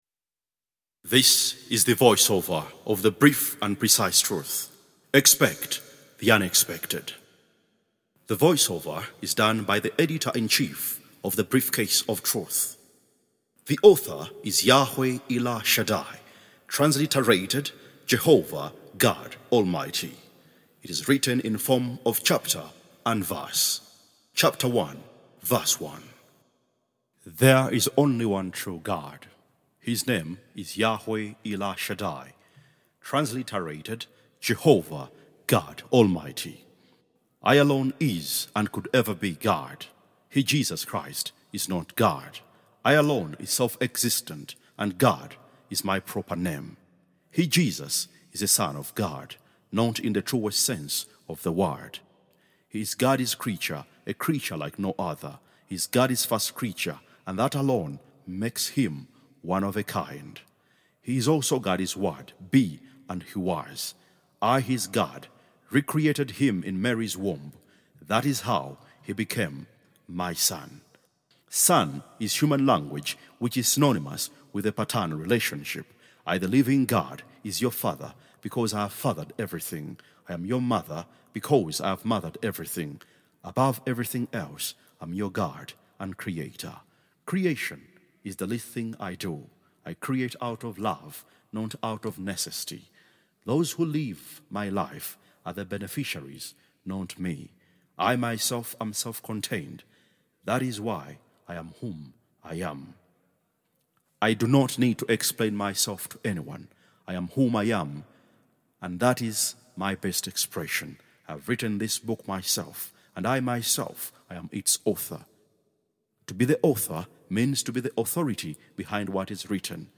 B.O.T.-VOICE-OVER.mp3